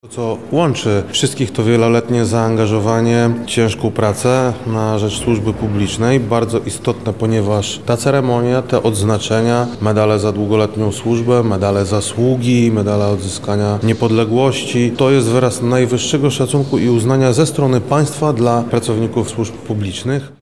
Dziś (21 października) w Lubelskim Urzędzie Wojewódzkim, wojewoda Krzysztof Komorski wraz z wicewojewodami wręczyli odznaczenia państwowe i resortowe nadane przez prezydenta RP.
Publicznie mamy najwyższy wskaźnik podpisanych umów i przekazanych fizycznie środków w całej Polsce, czego nie dokonał sam Urząd Wojewódzki, tylko zrobiliśmy to z państwem – samorządowcami” – mówi Krzysztof Komorski, wojewoda lubelski.